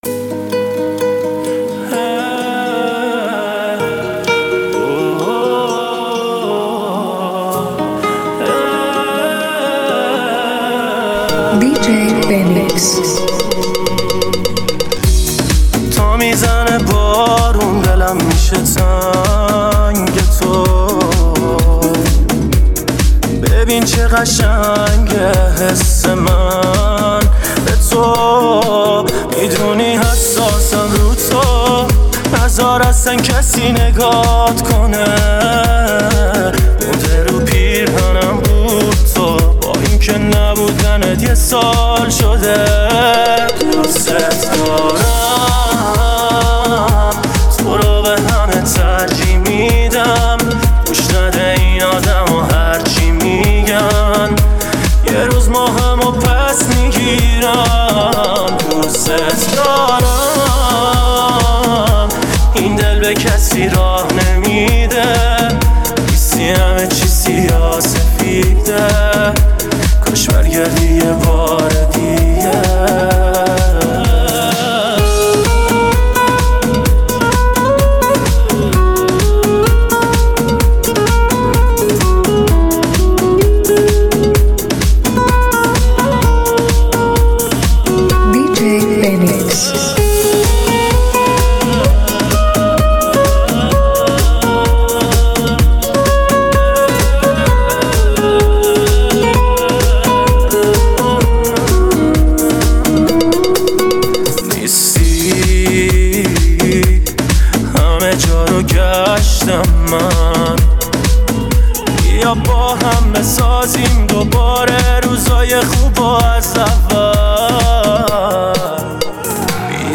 ریمیکس شاد و پرانرژی